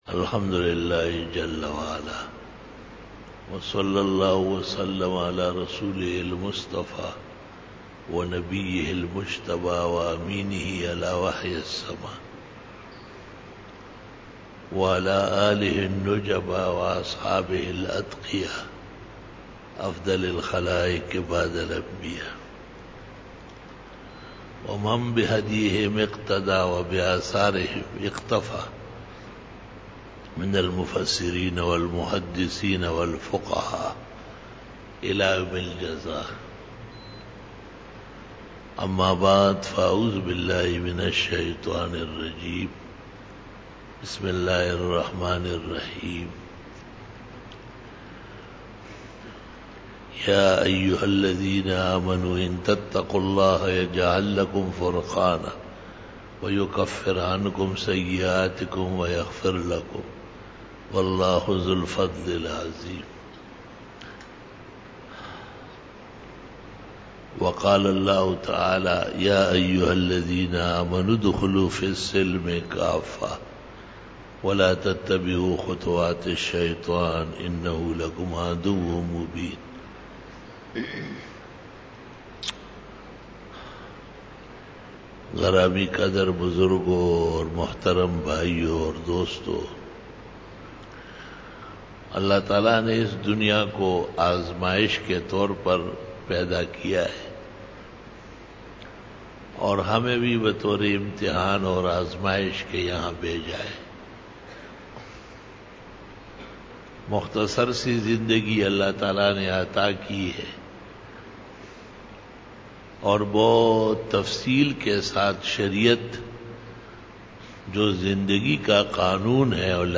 10 BAYAN E JUMA TUL MUBARAK (09 MARCH 2018) (20 Jamadi us Sani 1439H)